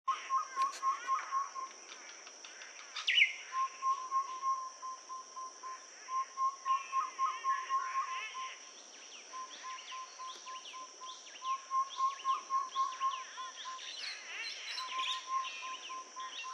White-spotted Flufftail (Sarothrura pulchra)
Location or protected area: Parque Nacional Lago Mburo
Condition: Wild
Certainty: Recorded vocal
White-spotted-Flufftail-mburo..mp3